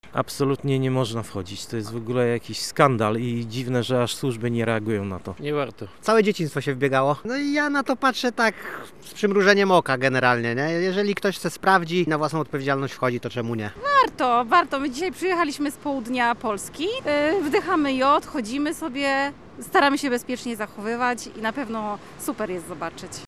O to przechodniów pytał nasz reporter.
lod-sonda.mp3